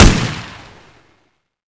pke/data/sounds/weapons/ump45-1.wav at 674e03349db8683cee0b025ba722c652f994ebb1
ump45-1.wav